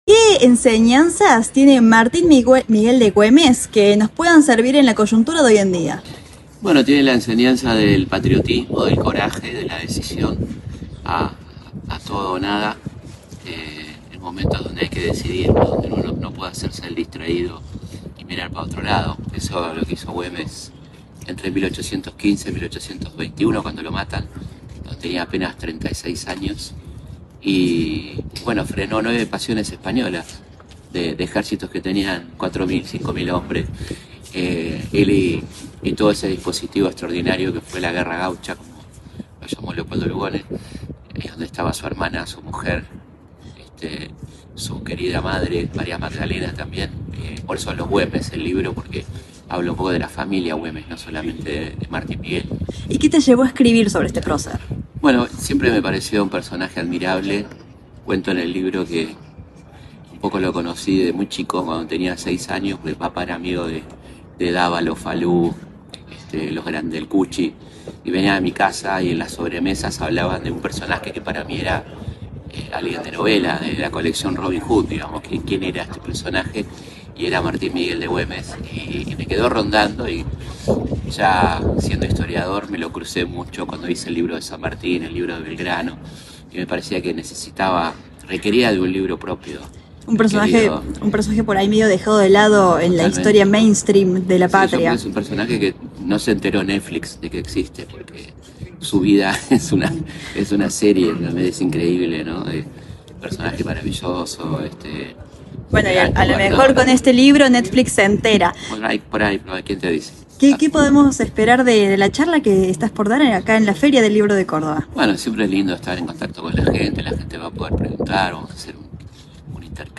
En un emotivo encuentro en la Feria del Libro de Córdoba, el reconocido historiador argentino Felipe Pigna presentó su más reciente obra literaria, dedicada a uno de los héroes olvidados de la historia argentina: Martín Miguel de Güemes
Informe